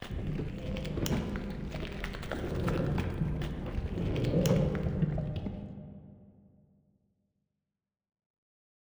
PixelPerfectionCE/assets/minecraft/sounds/mob/guardian/elder_death.ogg at mc116
elder_death.ogg